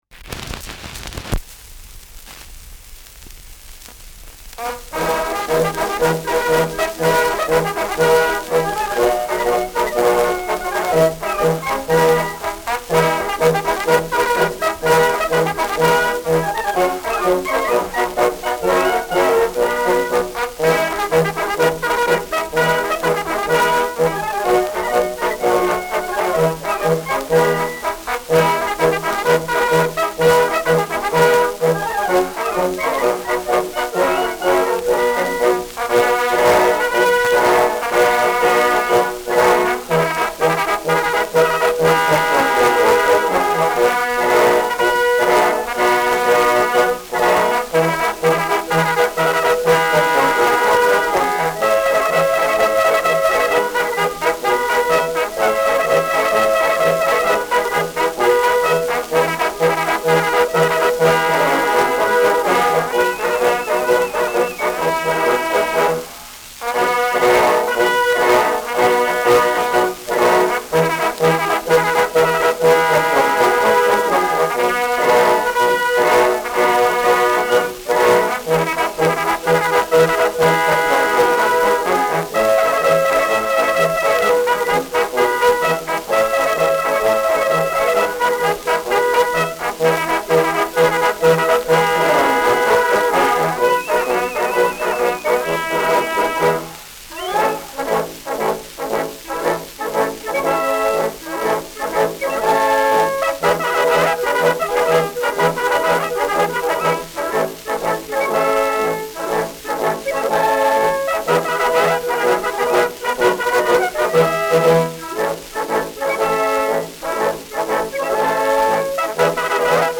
Schellackplatte
Tonrille: Kratzer 3 Uhr Leicht : Berieb Durchgehend Leicht
Pfeifen bei Auslaufrille